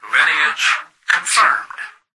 "Lineage confirmed" excerpt of the reversed speech found in the Halo 3 Terminals.